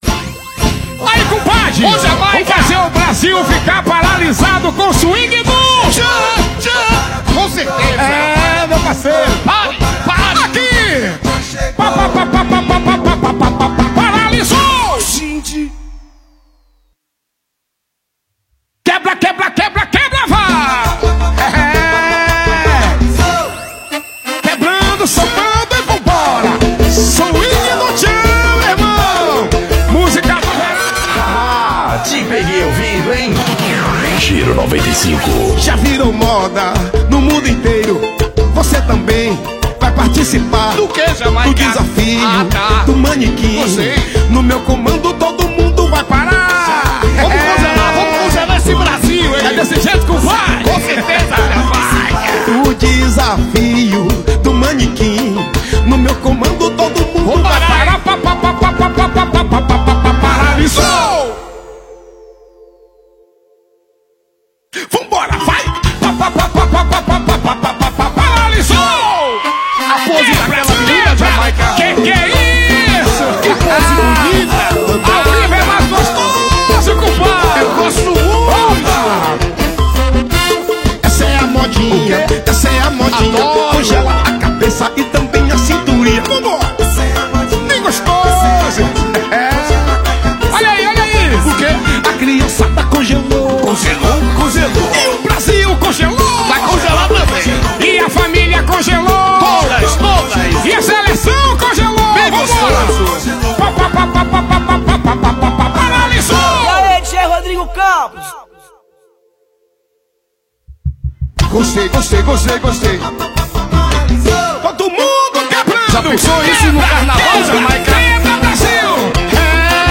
para quem gosta de muita folia